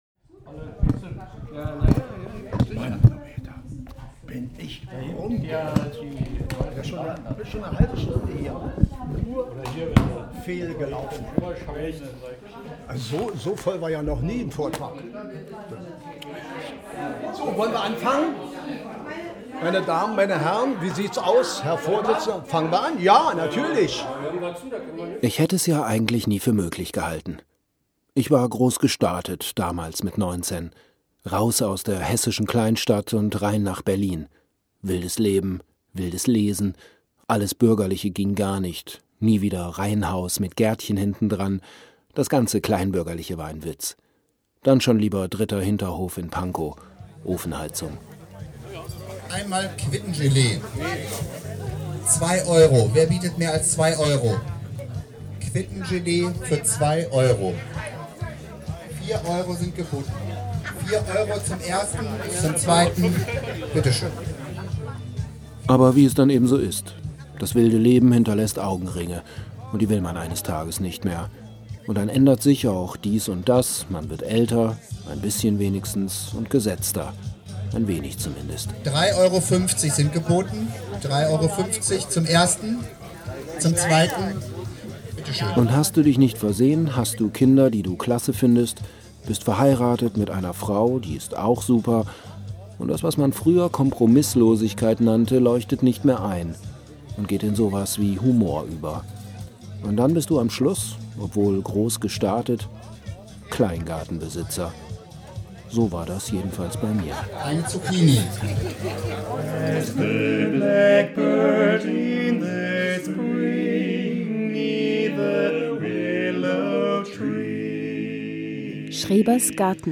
radiofeature
wir erfahren von einem dreifachmord in gifhorn, h�ren die acapella-truppe FLYING BARBERS singen und erfahren, da� der mensch kein vitamin C bilden kann. daneben gibt es drei geschichten von paule & krille, und drei infoblocks �ber die geschichte der schreberg�rten gibt es auch.